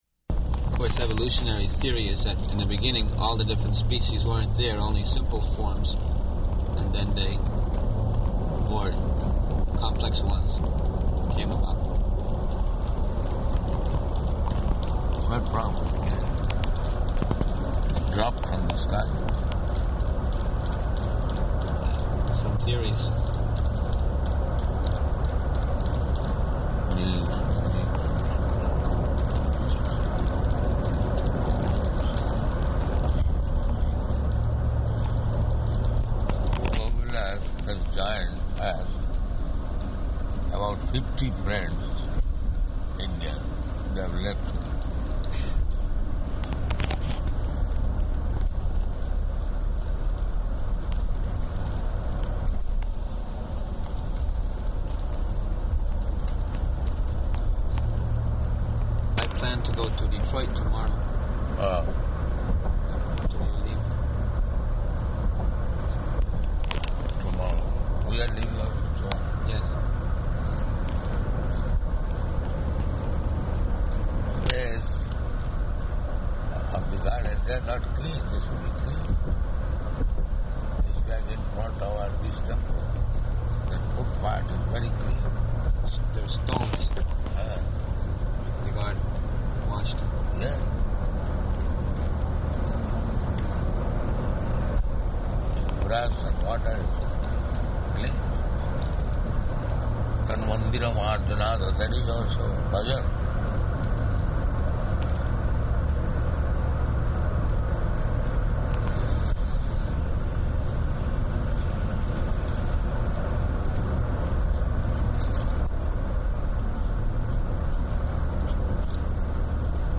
Type: Walk
June 20th 1976 Location: Toronto Audio file